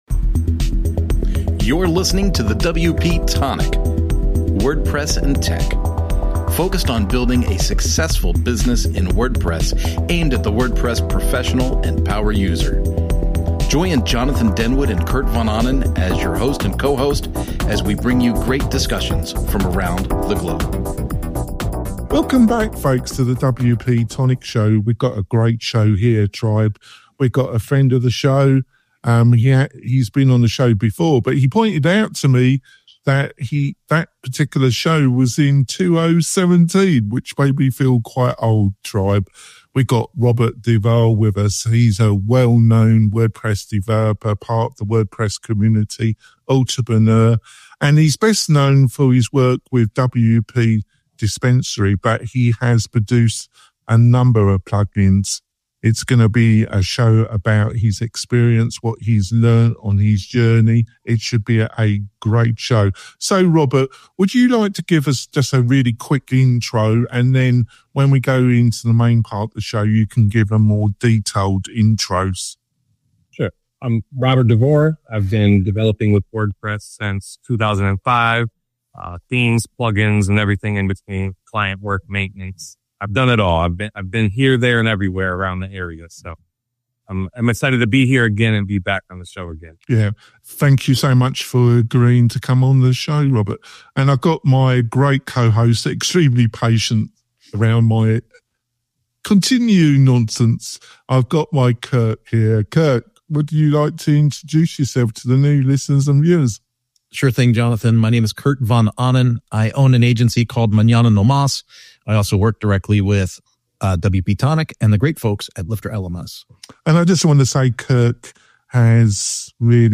We interview creative WordPress and startup entrepreneurs, plus online experts who share insights to help you build your online business.